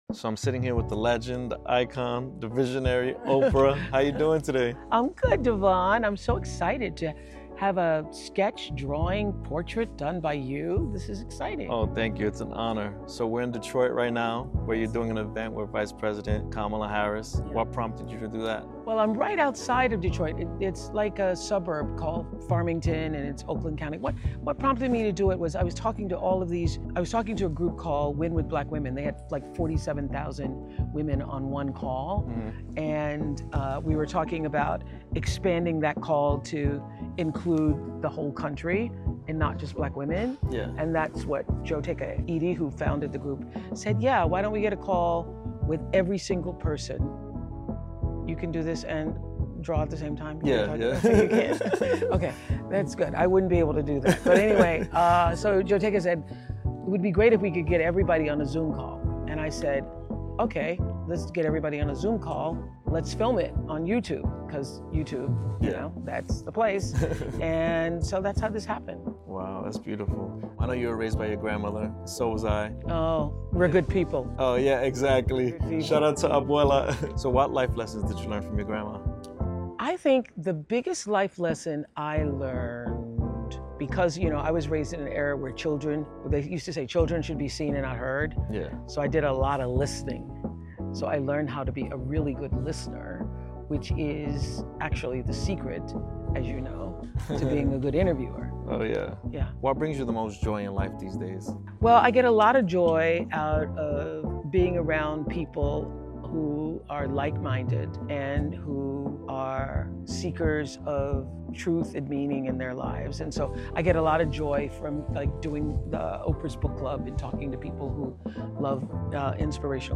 I’m beyond honored to have had the opportunity to interview the iconic Oprah. Never in my wildest dreams did I imagine meeting her, let alone sitting down for a conversation.